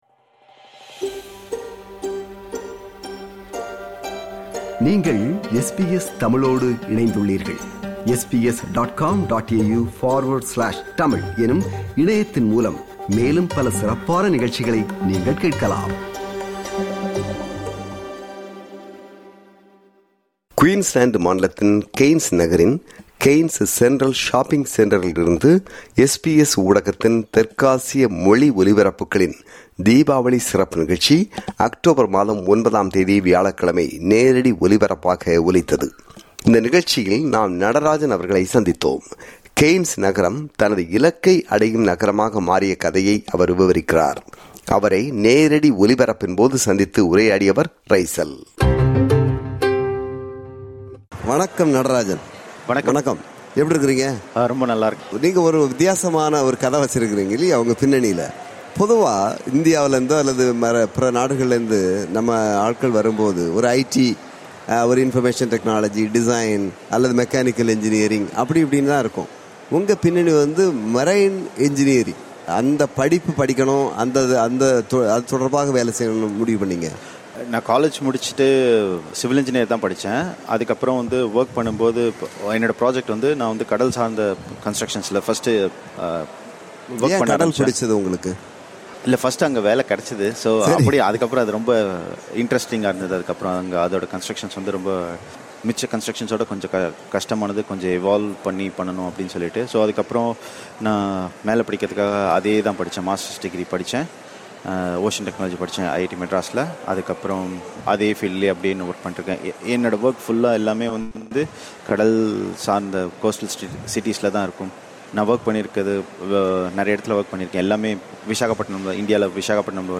How Cairns became the city where I achieved my goals SBS Radio’s South Asian language programs celebrated Deepavali with a live broadcast from Cairns Central Shopping Centre on Thursday, October 9.